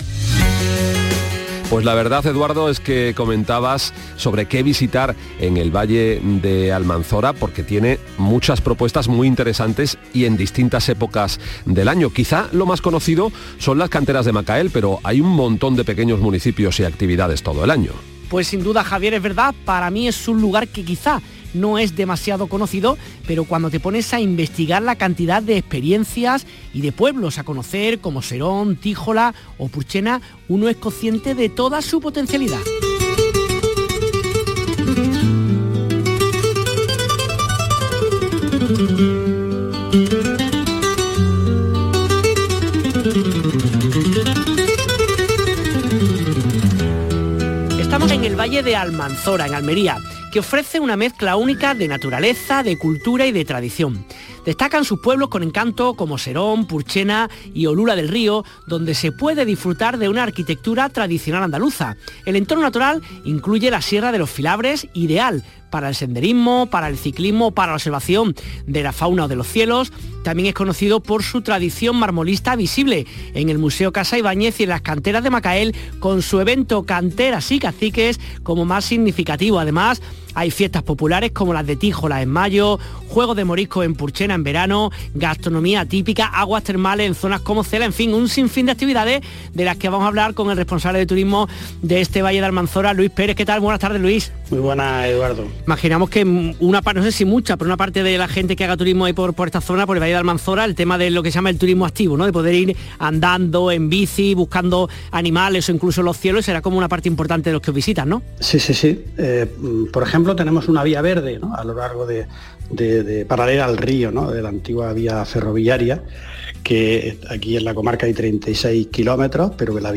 Corte del programa dedicado al Valle del Almanzora: